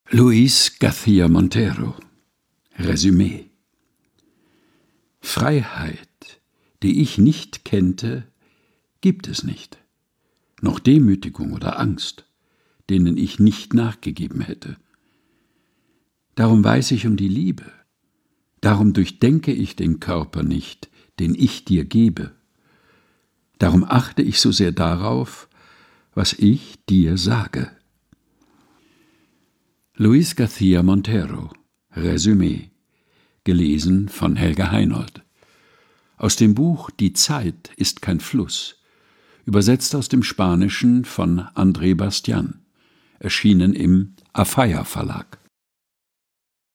Texte zum Mutmachen und Nachdenken